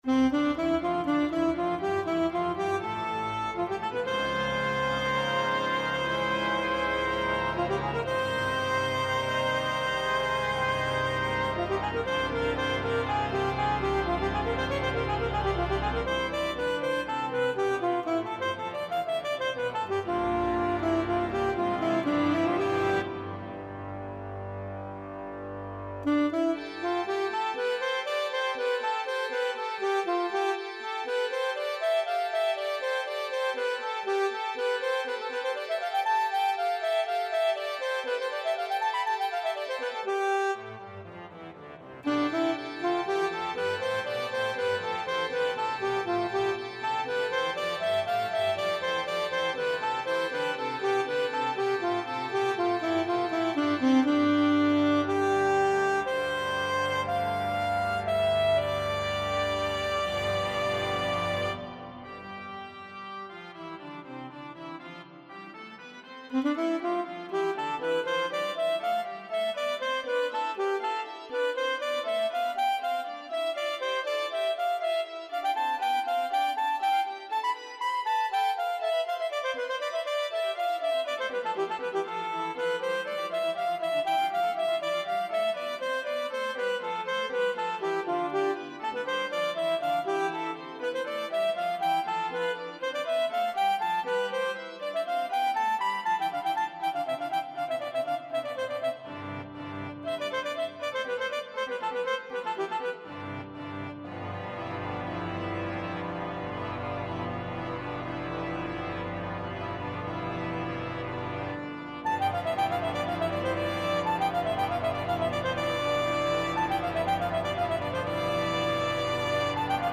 for organ and melody instrument
(Soprano-, Alto-Saxophone, Oboe)